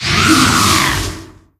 infinitefusion-e18/Audio/SE/Cries/SANDYGAST.ogg at a50151c4af7b086115dea36392b4bdbb65a07231